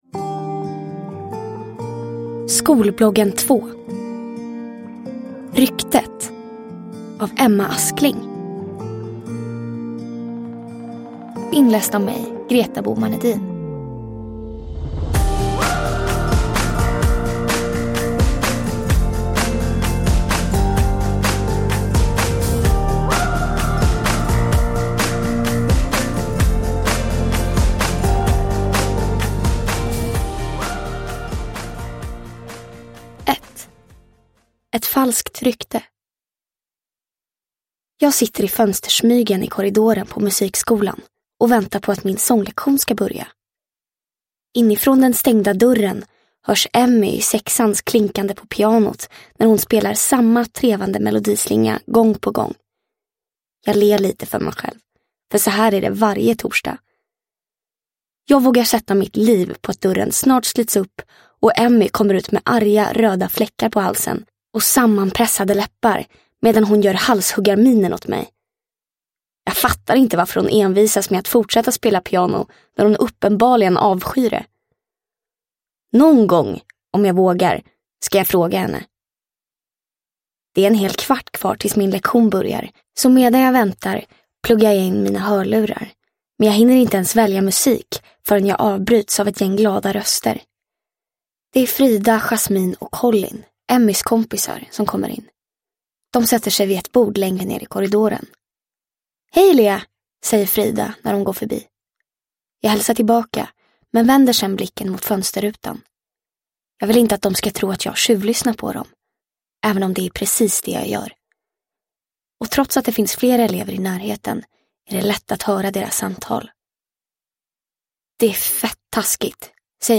Ryktet – Ljudbok – Laddas ner